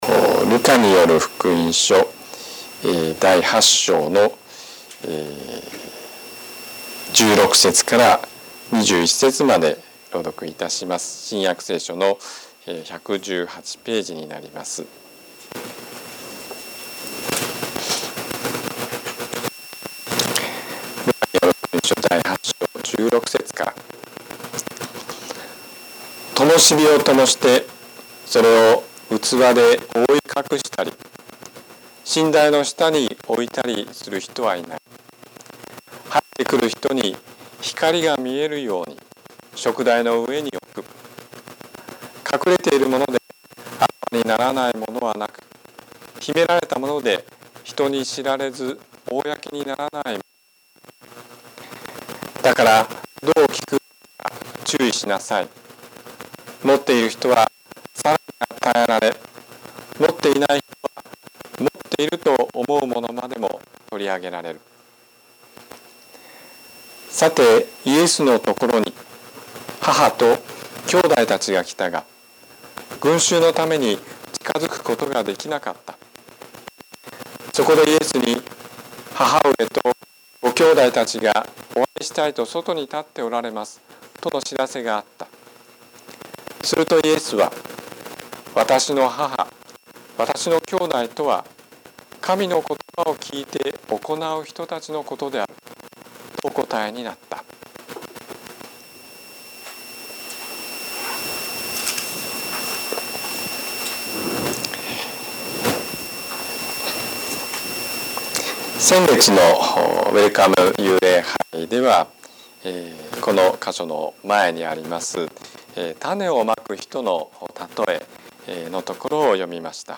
説教アーカイブ。